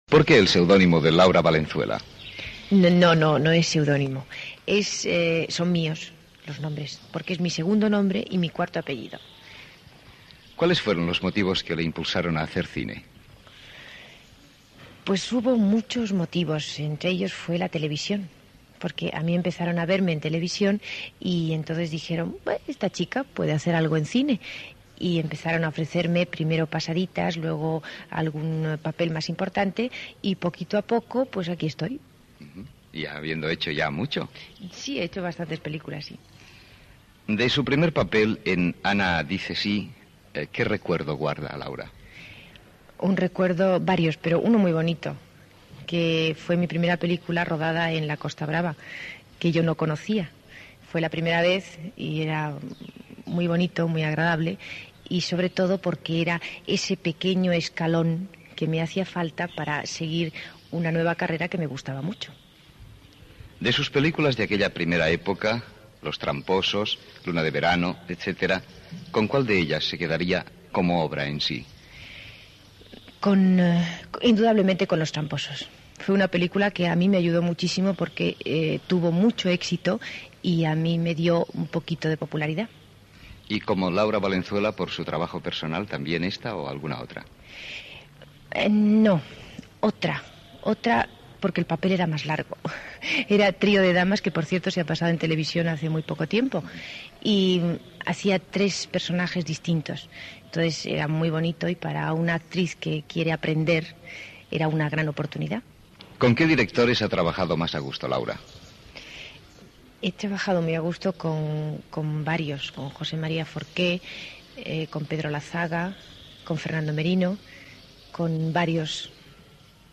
Entrevista a Laura Valenzuela (Rocío Espinosa) sobre la seva trajectòria al cinema.
Entreteniment